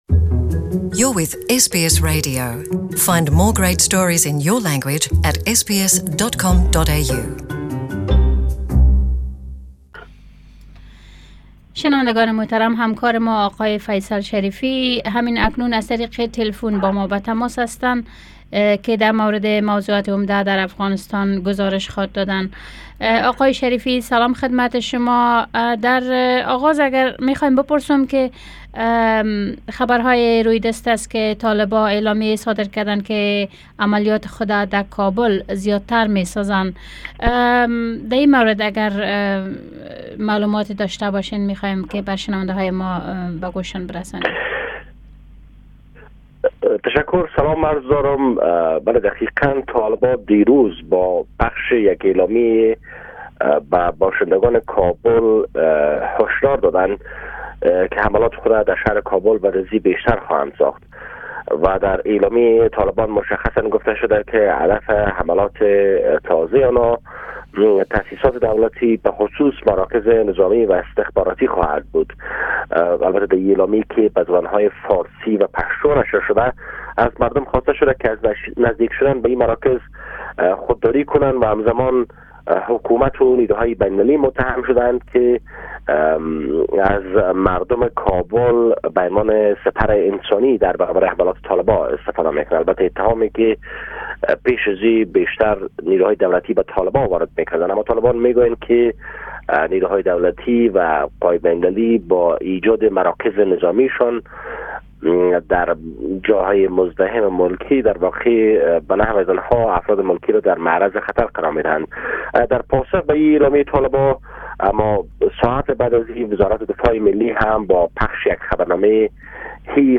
Report from Kabul
Our Reporter from Kabul Source: SBS